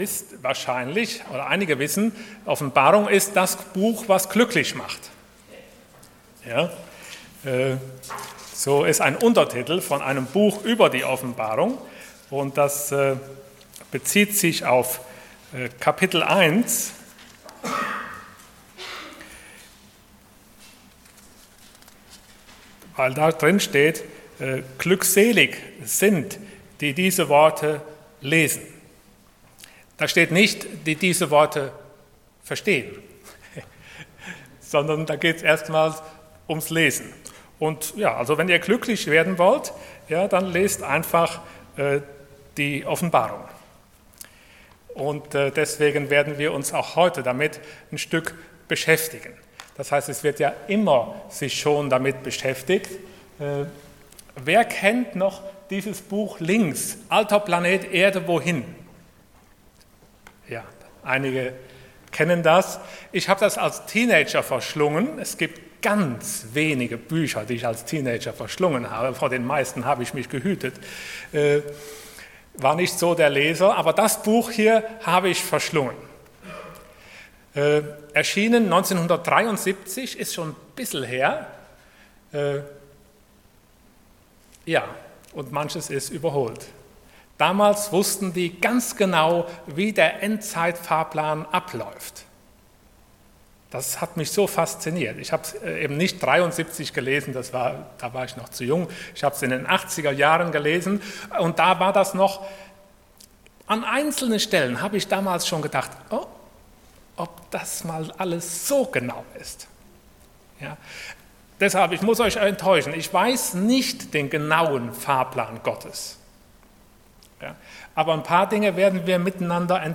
Passage: Revelation 12:1-13:18 Dienstart: Sonntag Morgen